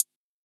UHH_ElectroHatB_Hit-31.wav